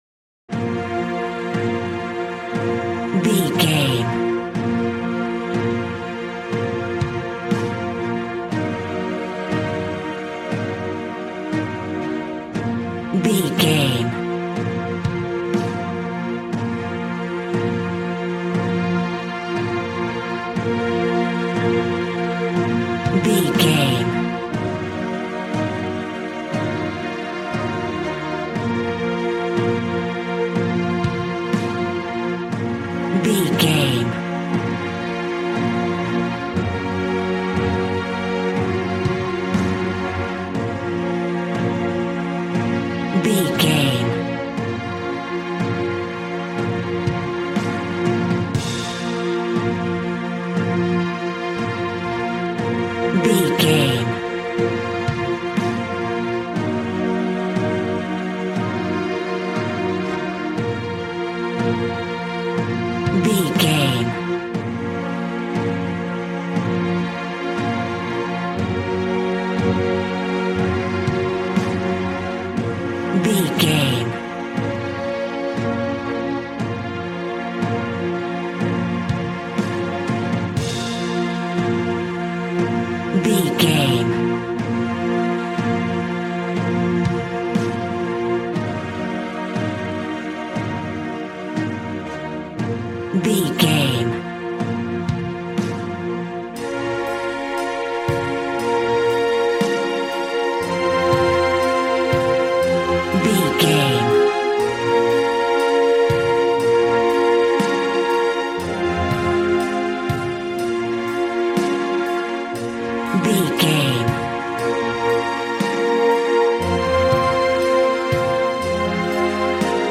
Aeolian/Minor
B♭
dramatic
strings
violin
brass